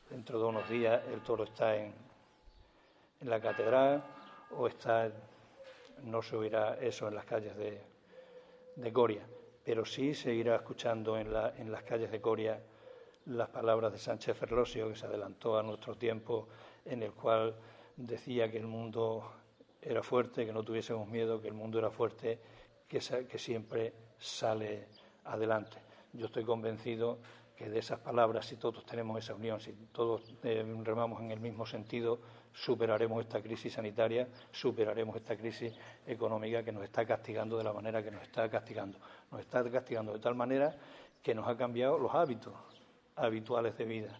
CORTES DE VOZ
Carlos Carlos_ Presidente-Diputación.-Cuento-Coria.